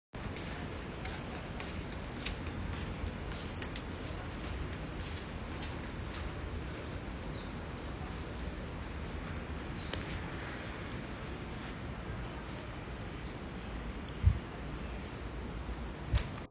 大同區南京西路64巷巷弄
均能音量: 53.2 dBA 最大音量: 78 dBA 地點類型: 巷弄
說明描述: 安靜，但還是有遠處車聲及冷氣機呼呼聲 聲音類型: 車輛、馬達聲、雷